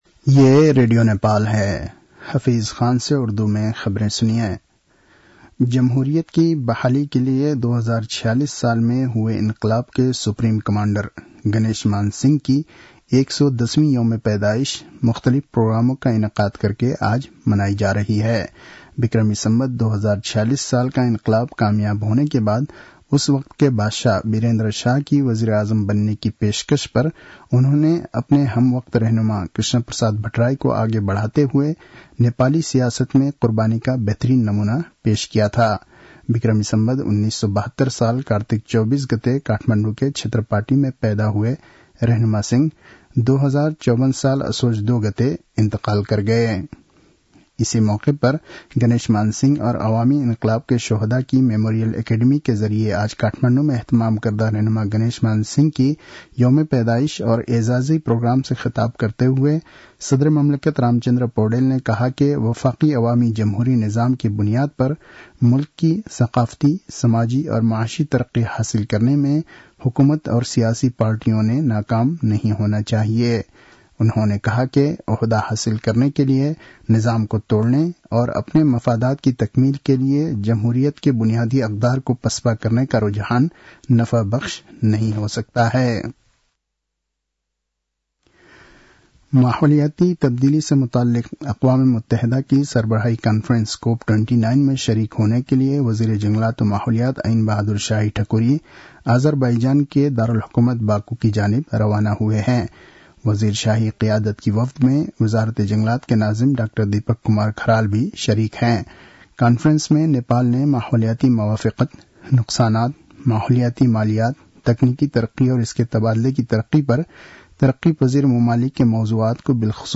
An online outlet of Nepal's national radio broadcaster
उर्दु भाषामा समाचार : २५ कार्तिक , २०८१